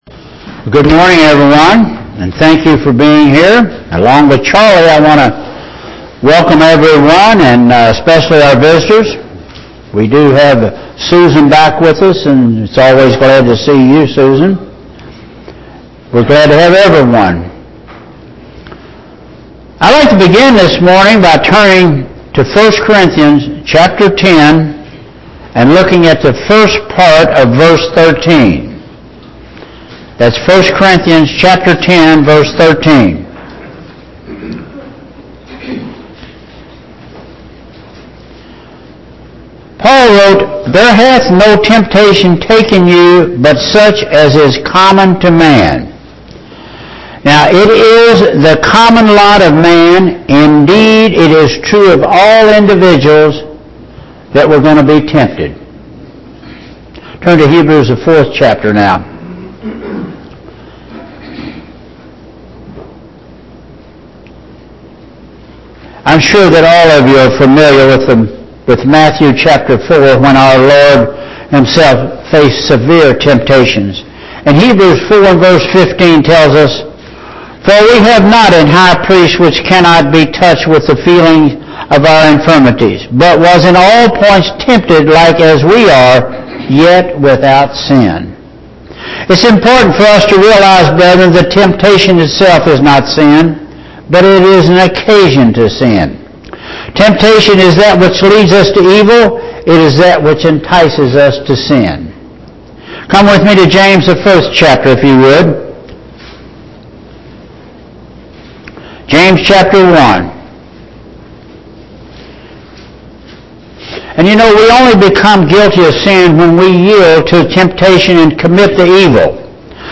Audio Sermons 2014